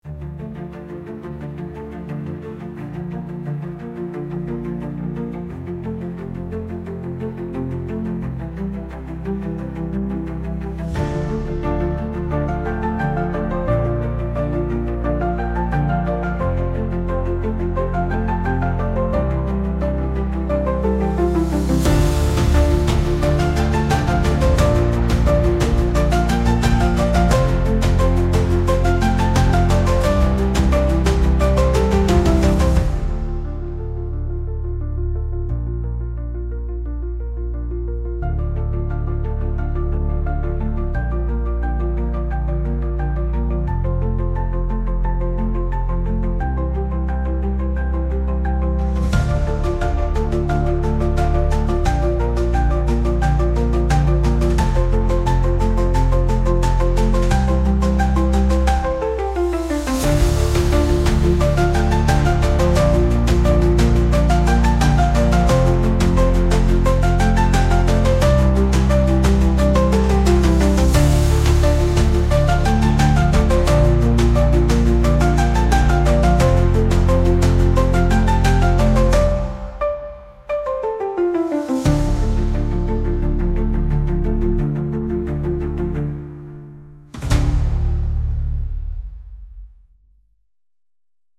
Genre: Build Up Scenes / Beautiful Plays / Main Title
Mood: Restless / Bright / Uplifting / Epic
Movement: Chasing / Medium